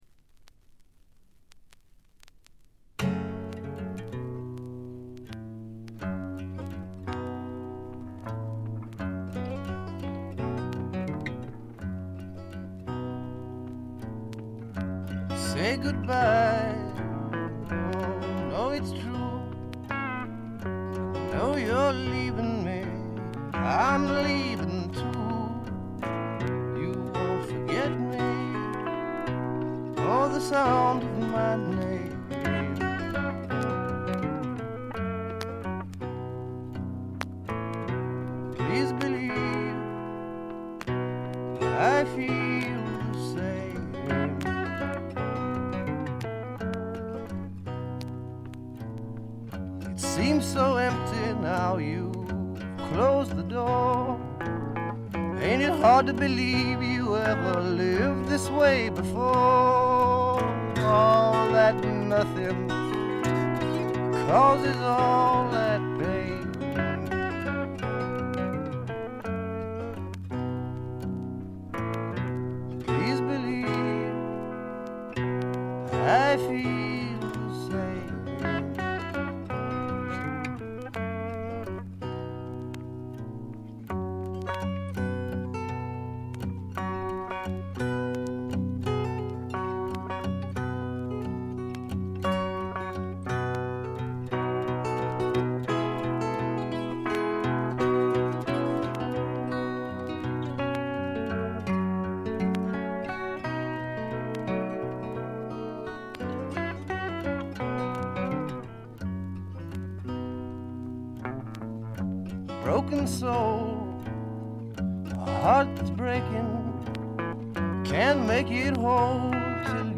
バックグラウンドノイズ、チリプチ多め大きめです。
試聴曲は現品からの取り込み音源です。